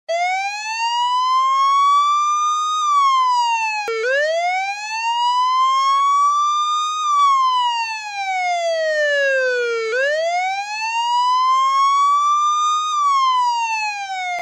Transcription: Sirens
Sirena.mp3